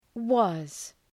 Shkrimi fonetik {wɒz}